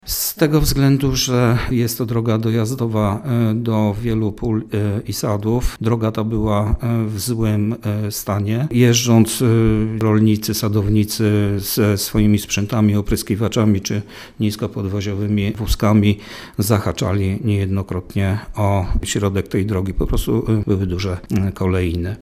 – O potrzebie poprawienia nawierzchni drogi dojazdowej do gruntów rolnych w miejscowości Błonie właściciele zlokalizowanych tam sadów i pól uprawnych mówili od dawna – mówi wiceburmistrz Koprzywnicy Mirosław Kępa: